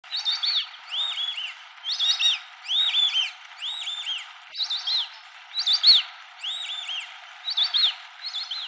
Dendrocygna_viduata1_flock-online-audio-converter.com_.mp3